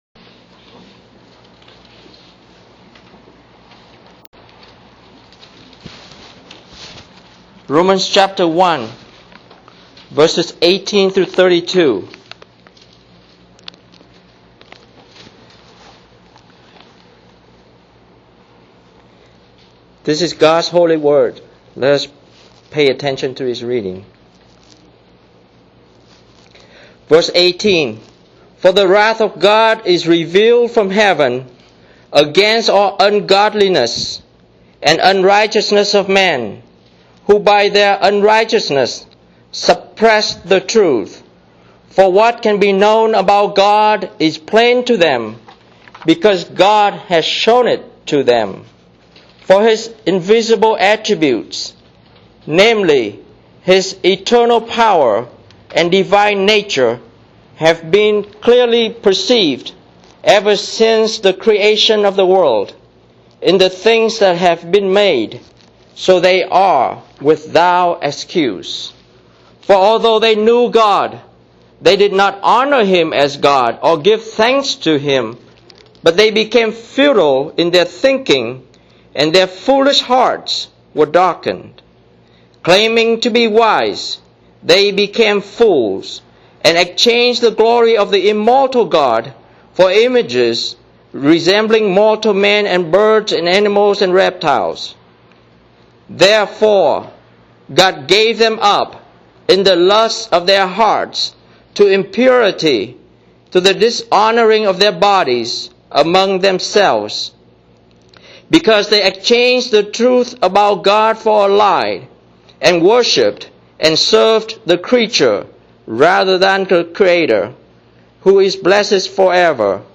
Christ and Heaven OPC: [Sermon] Romans 1:18-32